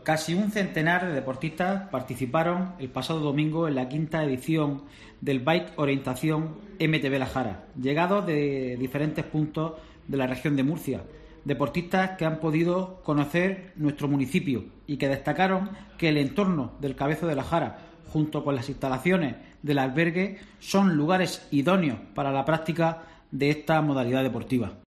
Juan Rubén Burrueco, edil deportes de Pto Lumbreras.